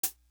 Soul Hat.wav